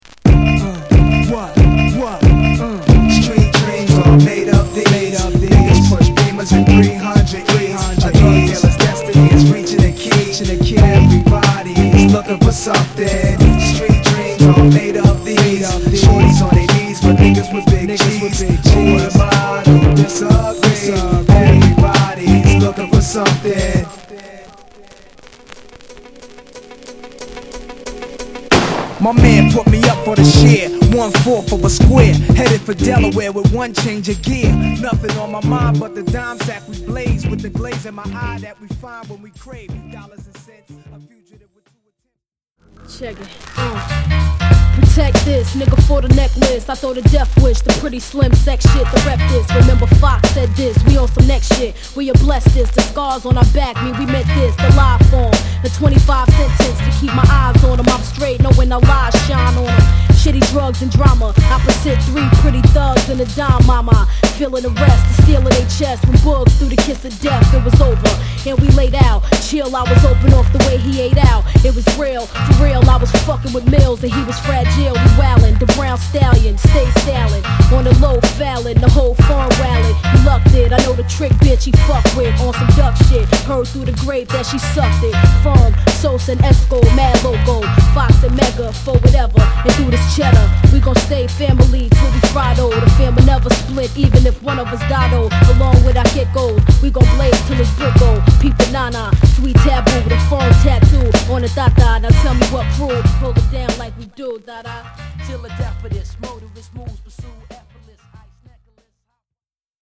Streetの厳しさをリアルかつ巧妙に描写するNasのリリシスト振りを存分に感じることができる名作！